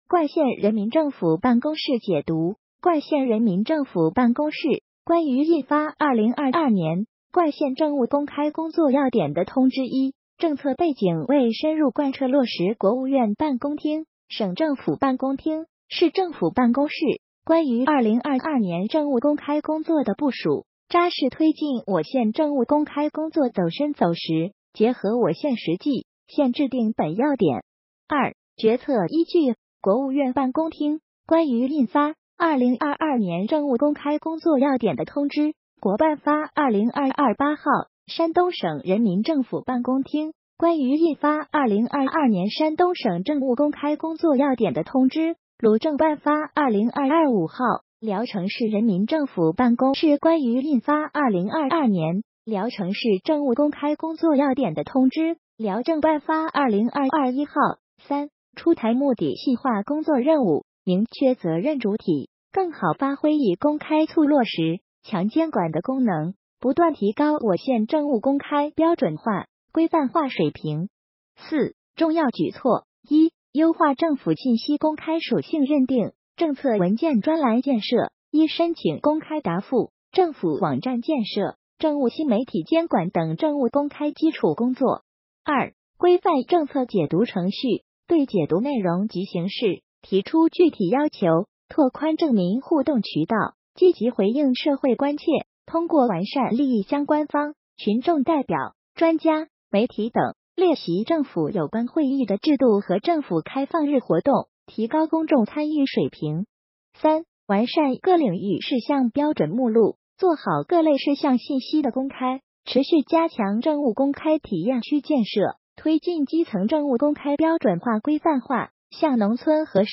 音频解读：《冠县人民政府办公室关于印2022年冠县政务公开工作要点的通知》.mp3